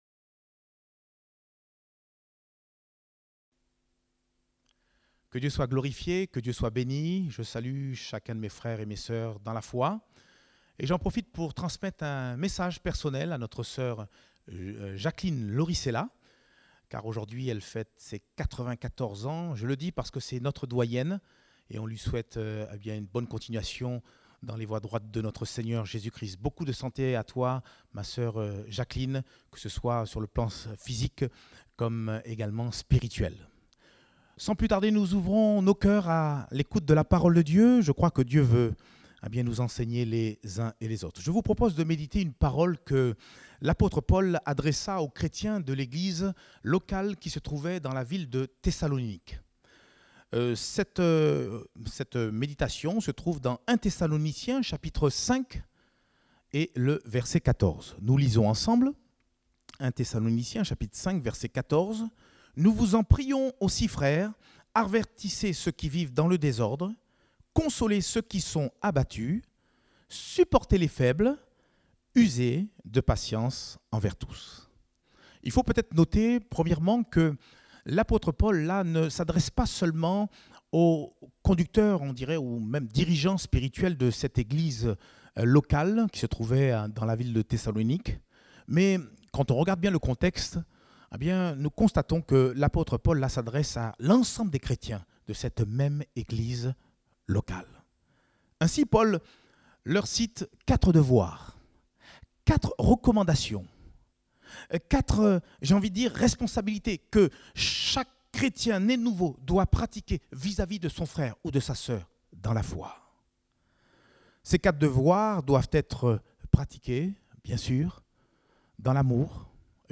Date : 22 novembre 2020 (Culte Dominical)